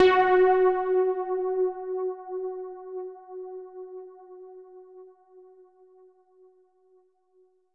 SOUND  F#3.wav